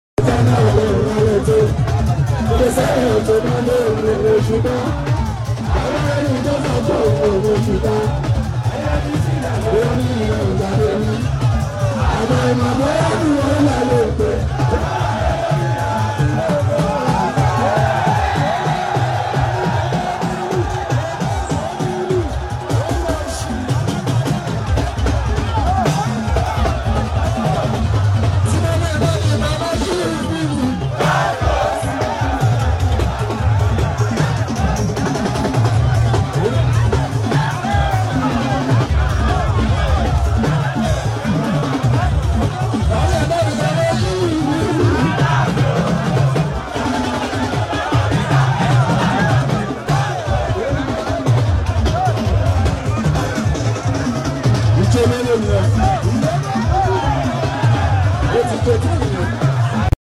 songs on stage